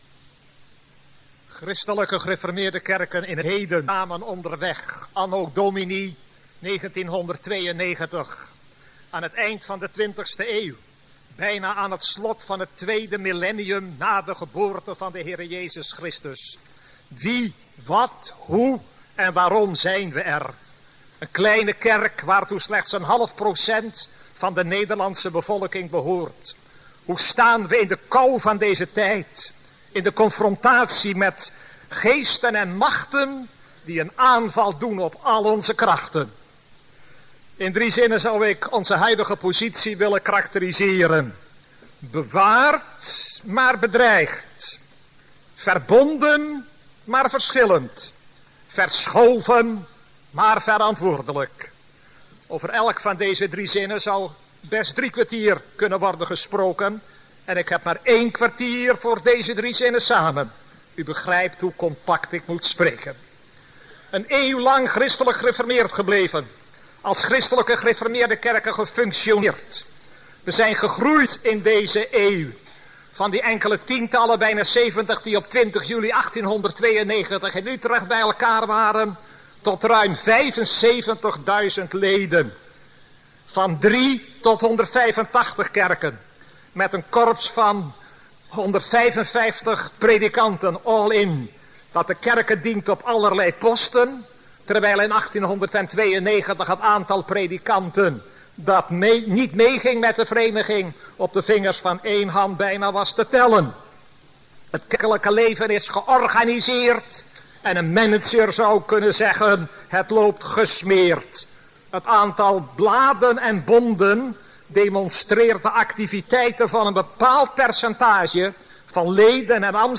Lezing 1992 - Wat, hoe en waarom zijn we er.